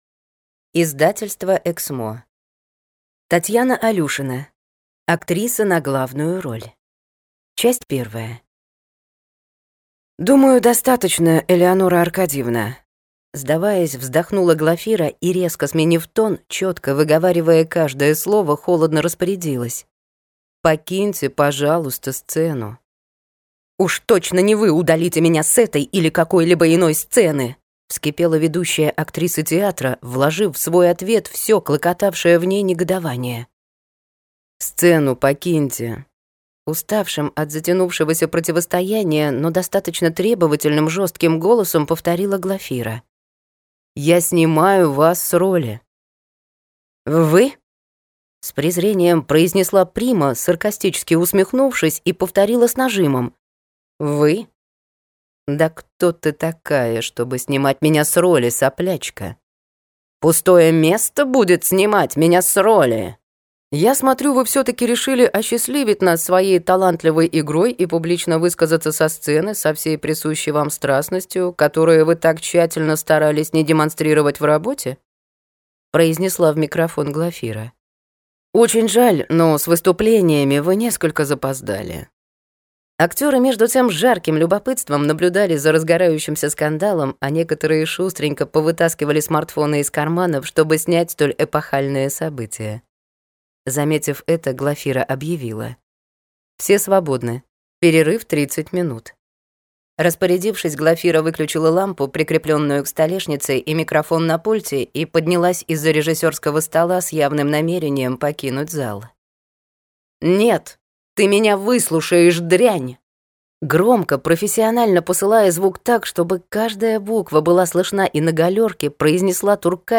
Аудиокнига Актриса на главную роль | Библиотека аудиокниг